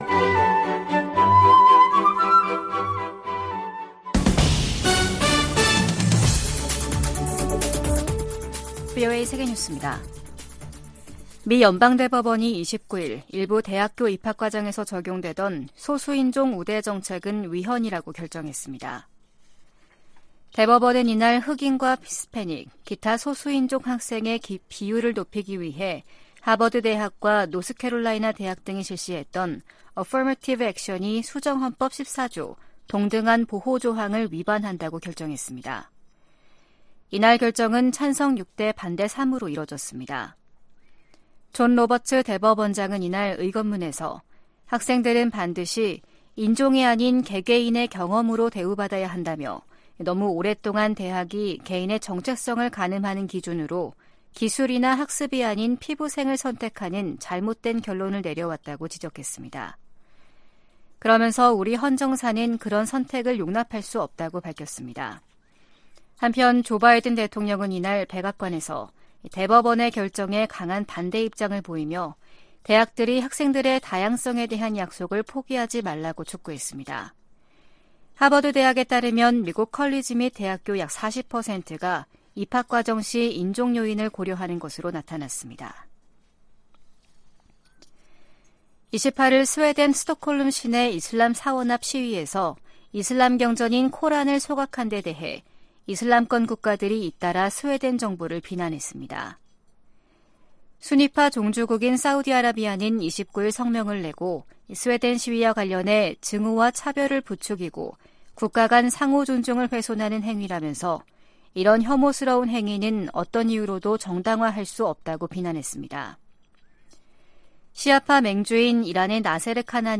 VOA 한국어 아침 뉴스 프로그램 '워싱턴 뉴스 광장' 2023년 6월 30일 방송입니다. 커트 캠벨 백악관 국가안보회의(NSC) 인도태평양 조정관은 미한일 정상회담에서 3국 협력 가능 방안을 모색할 것이라고 말했습니다. 북한은 인신매매를 정부 정책으로 삼고 있는 최악의 인신매매 국가라고 국무부 고위관리가 지적했습니다.